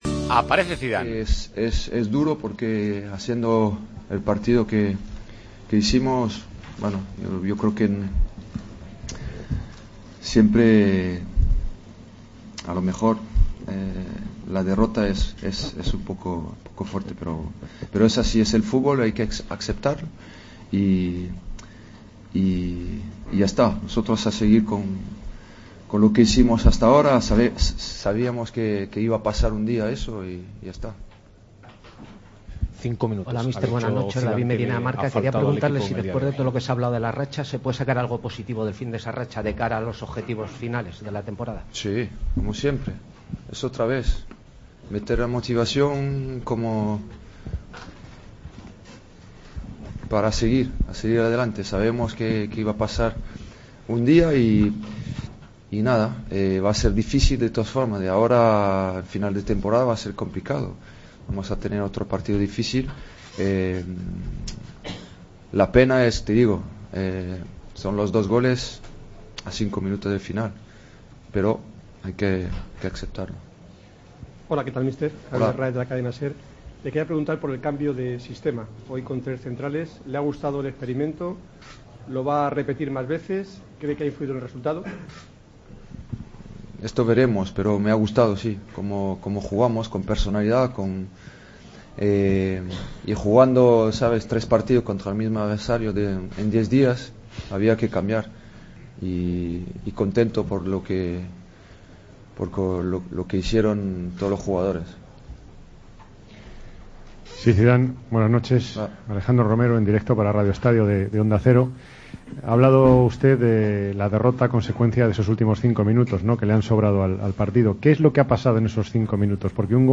El técnico del Real Madrid habla de la derrota frente al Sevilla en rueda de prensa: "La derrota es un poco fuerte, pero sabíamos que iba a pasar un día.